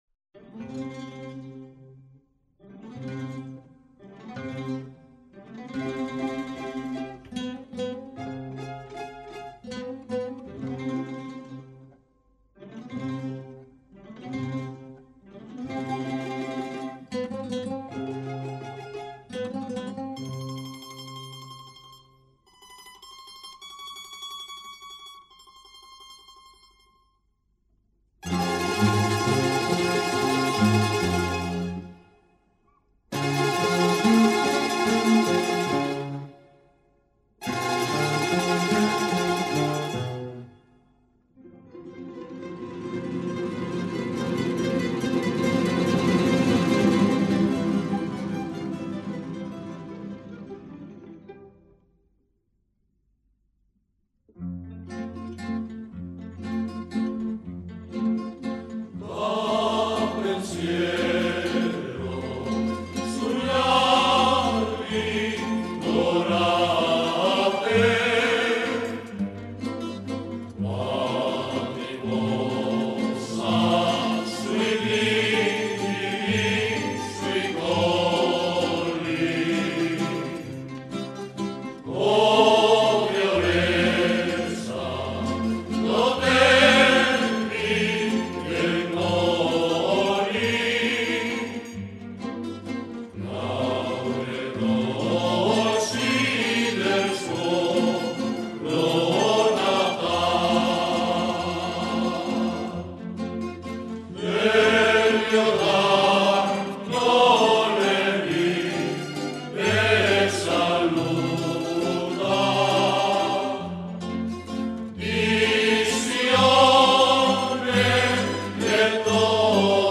Habaneras